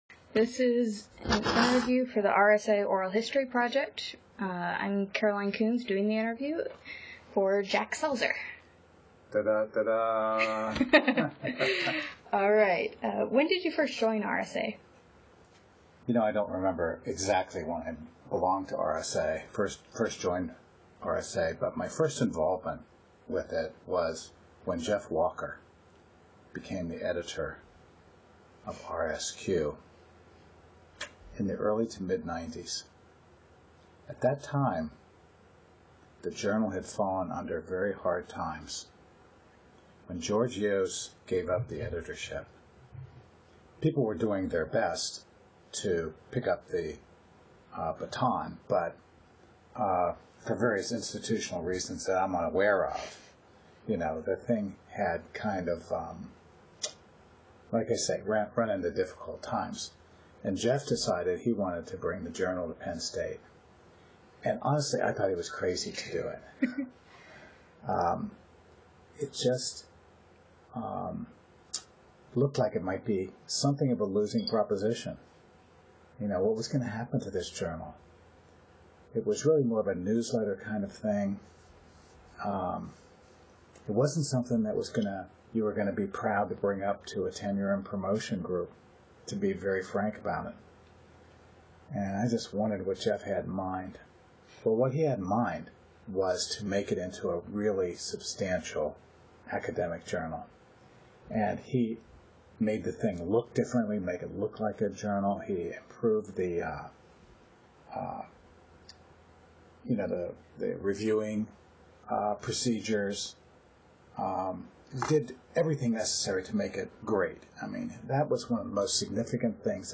Oral history interview
Location State College, Pennsylvania